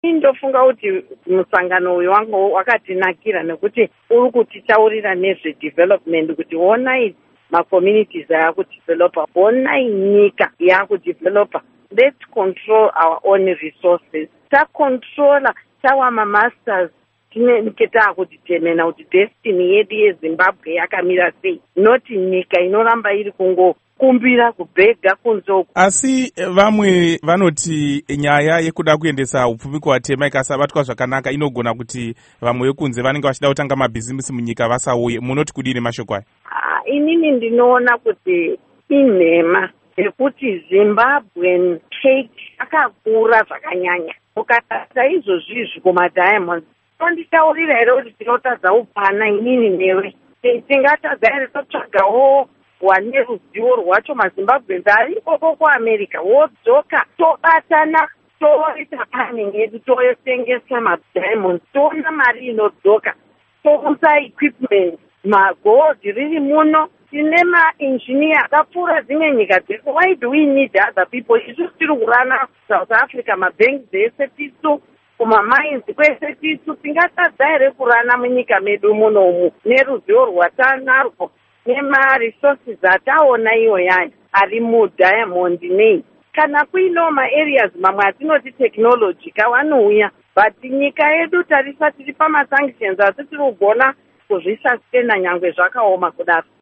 Hurukuro naMuzvare Oppah Muchinguri